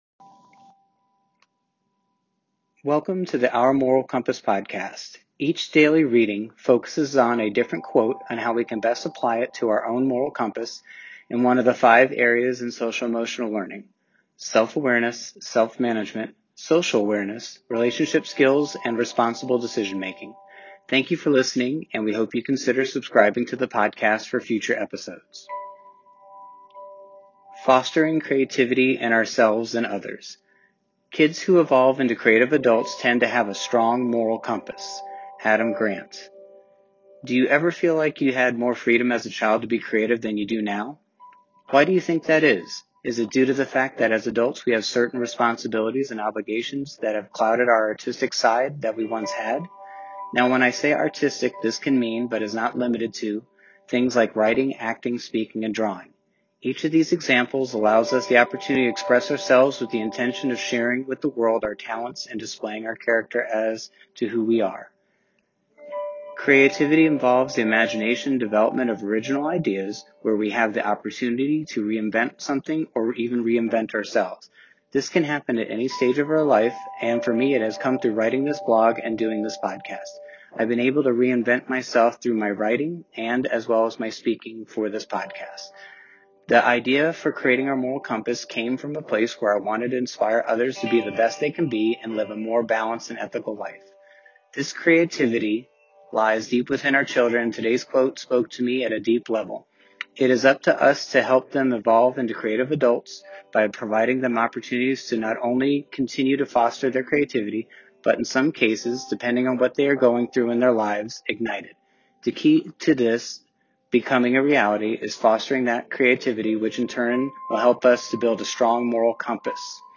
Each daily reading focuses on a different quote on how we can best apply it to our own moral compass and one of the five areas in Social Emotional Learning: Self-Awareness, Self-Management, Social Awareness, Relationship Skills and Responsible Decision Making.